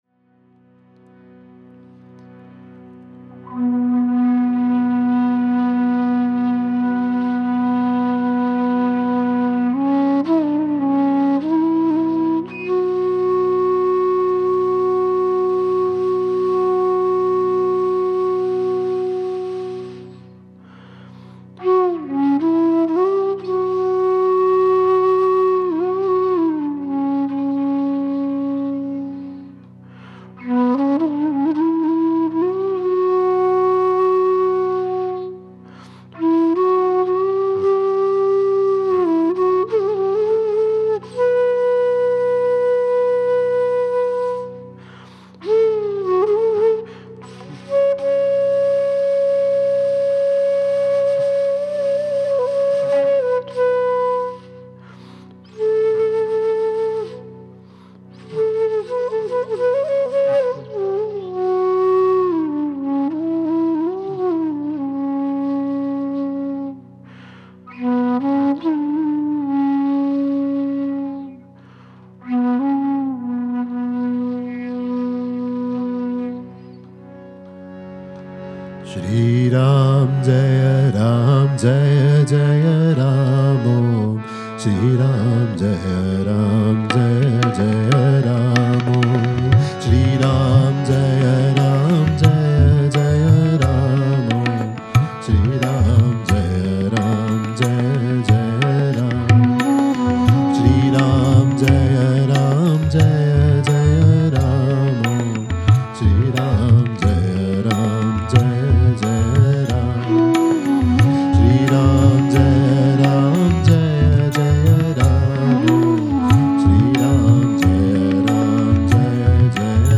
I have named it Arise as this version creates quite an intense energy and I find it really uplifting.
Vocals
Flute
Tabla
Manjira